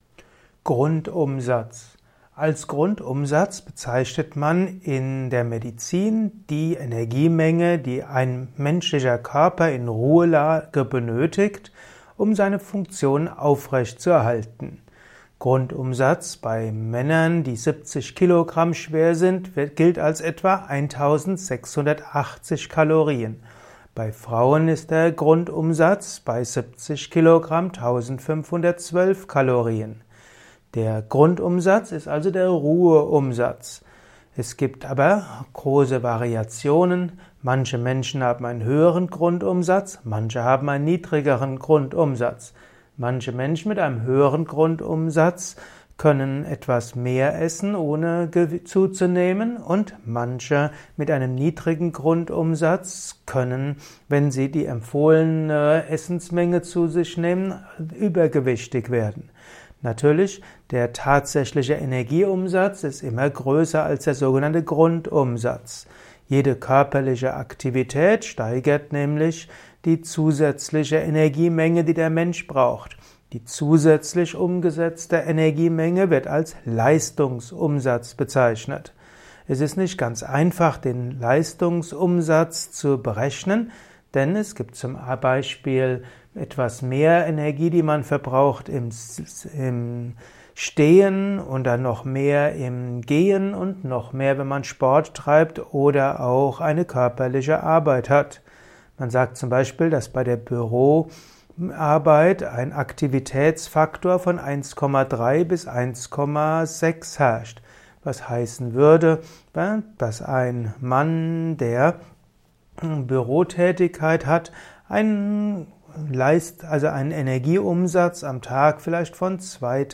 Simple und komplexe Infos zum Thema Grundumsatz in diesem Kurzvortrag